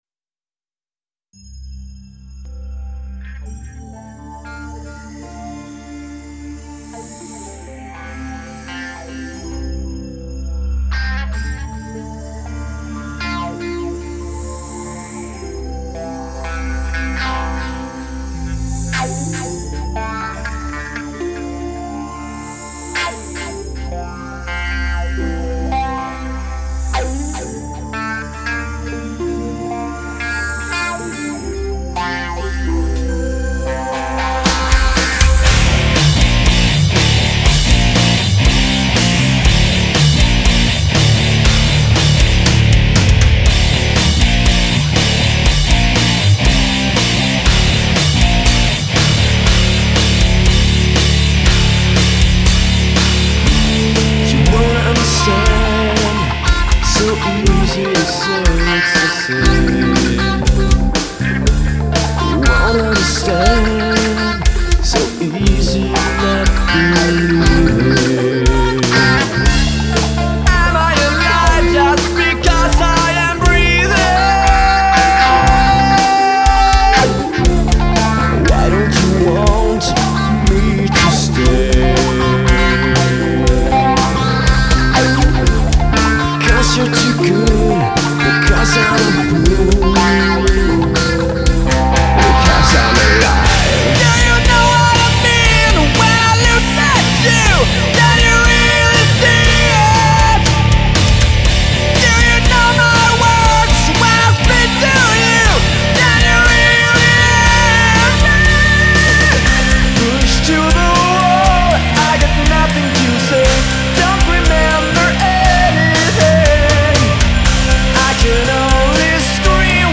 Here are some of my music productions, that I have recorded using my “livingroom” homestudio back in the days.
Meanwhile you can listen listen my old music productions I’ve recorded using my computer as a digital audio workstation (D.A.W.).